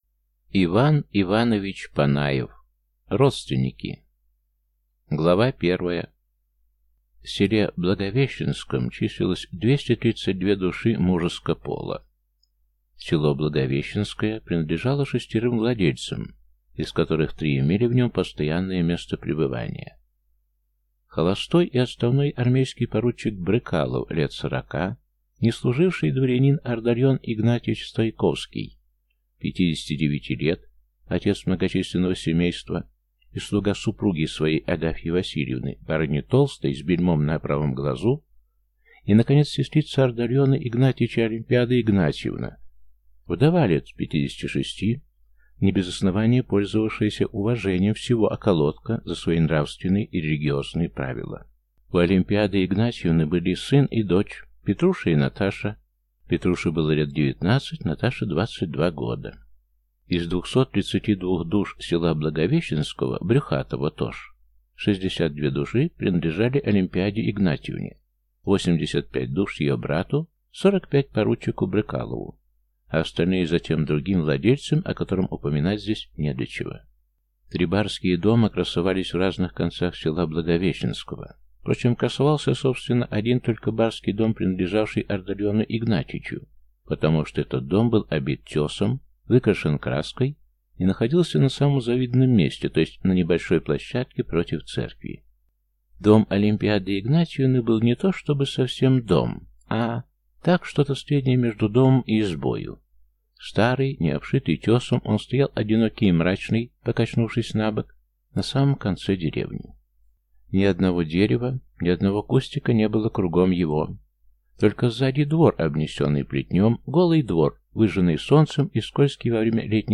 Аудиокнига Родственники | Библиотека аудиокниг